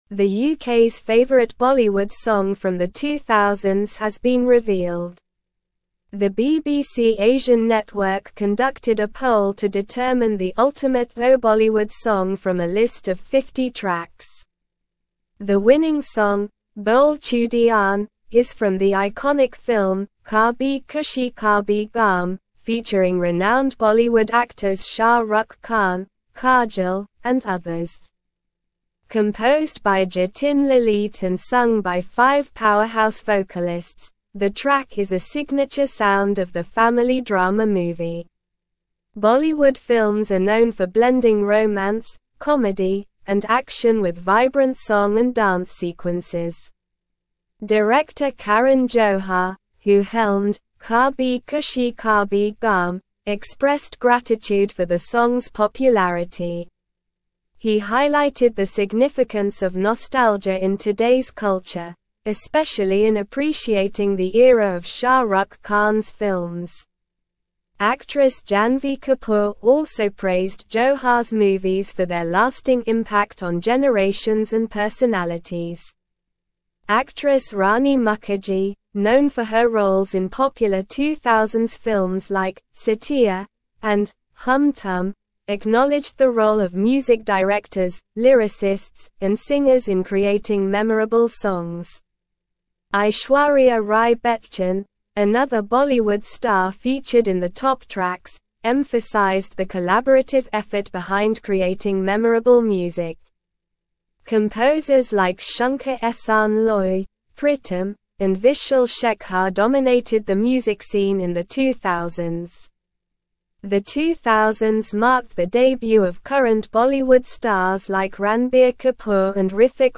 Slow
English-Newsroom-Advanced-SLOW-Reading-UKs-Favorite-Bollywood-Song-from-Noughties-Revealed.mp3